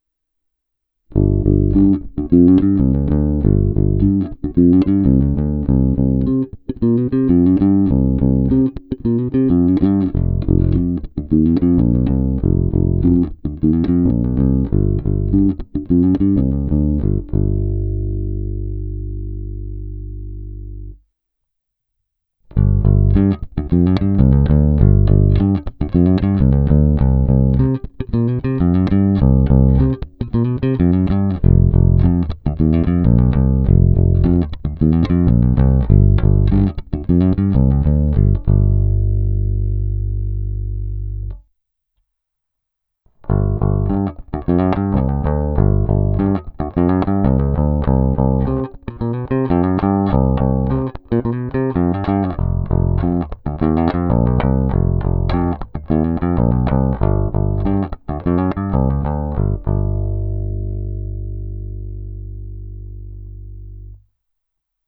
Ukázka přepínače filtru, kterou jsem provedl čistě na krkový snímač, začíná polohou přepínače nahoře, navazuje střední poloha a končí to polohou dole. Horní poloha, jak slyšíte, má výrazné nižší středy kolem 300 Hz, střední poloha je řekl bych neutrální, spodní poloha zvýrazňuje vyšší středy okolo 800 Hz.